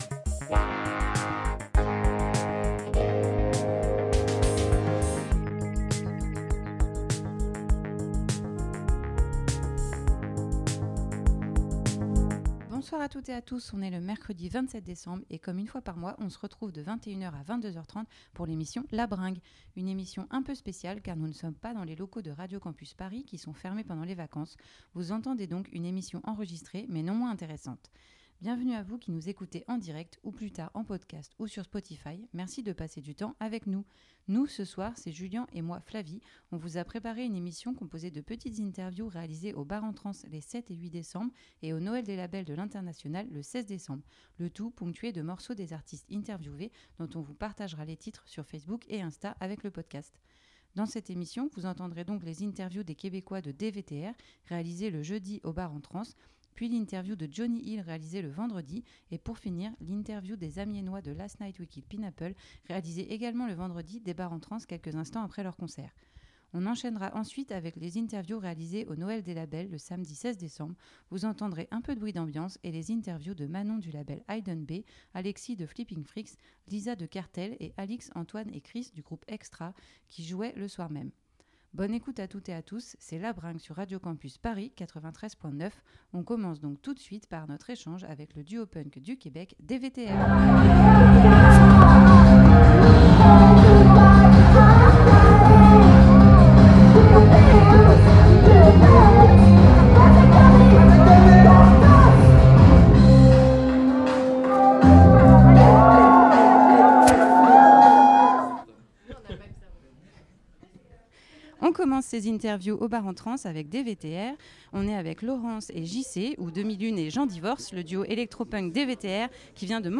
Pour la troisième émission de cette saison, l’équipe de La Bringue a promené son micro du côté des Bars en Trans à Rennes et au Marche de Noël de l'International.